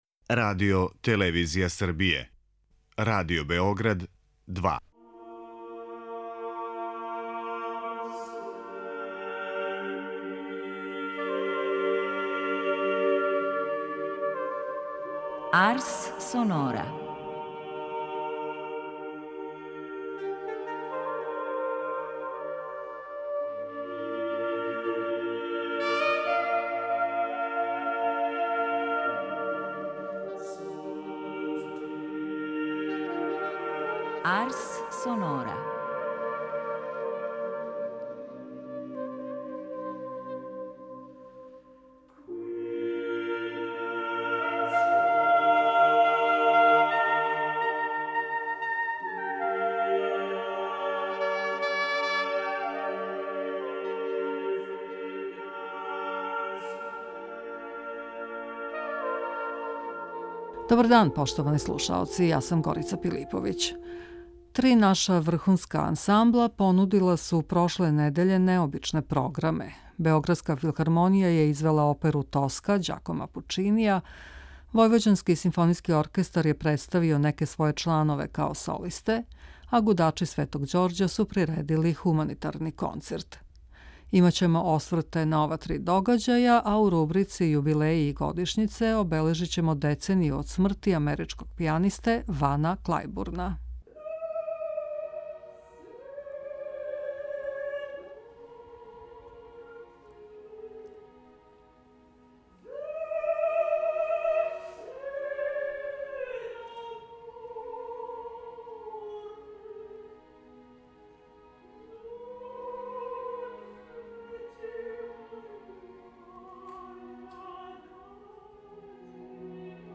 За данашњу емисију Арс сонора Музичка редакција Радио-Београда 2 одабрала је да прати концерте двају оркестара – Војвођанских симфоничара и Београдске филхармоније, као и наступ Гудача Св.Ђорђа.
Два оркестра су одржала концерте истог дана, 24. фебруара, сваки у свом граду, Новом Саду односно Београду.
Гудачи св.Ђорђа су одржали хуманитарни концерт за угрожене ратом у Украјини.